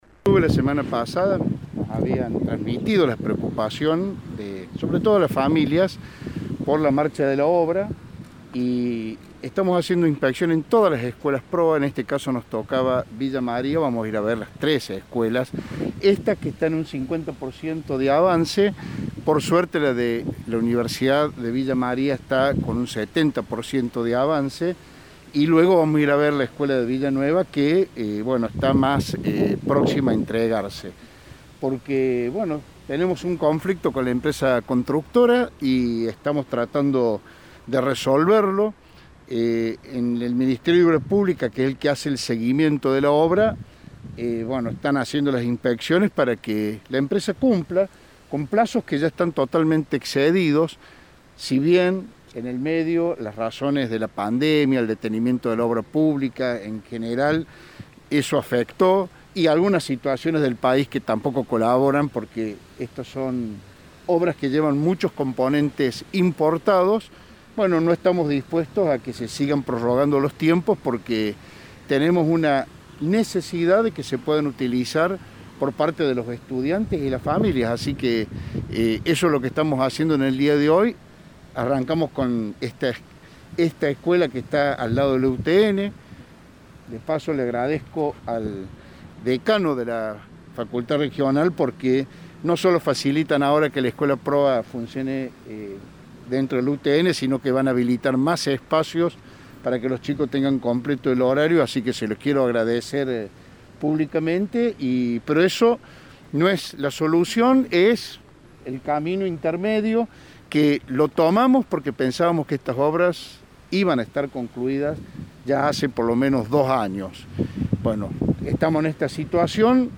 En la oportunidad, Grahovac habló con la prensa.